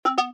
Provide audio feedback as soon as the recording fails.
error_notification.mp3